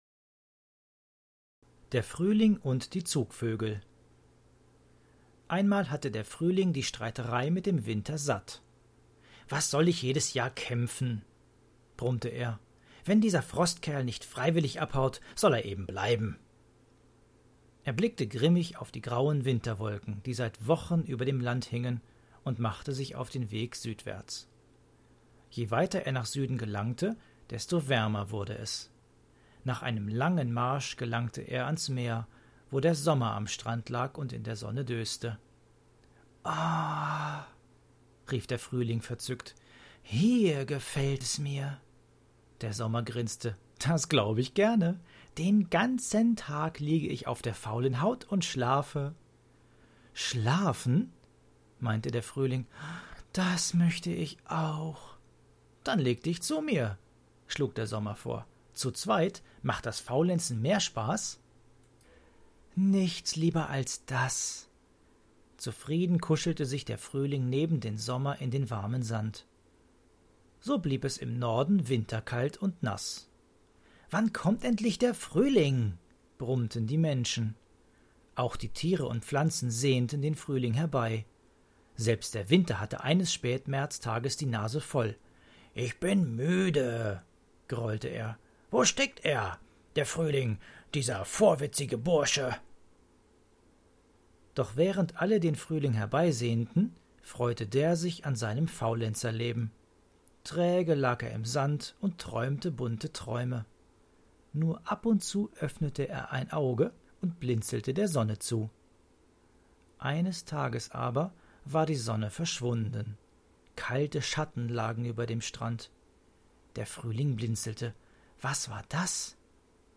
Frühlingsmärchen – Einmal waren es die Zugvögel, die den Frühling aus seinem Winterschlaf aufweckten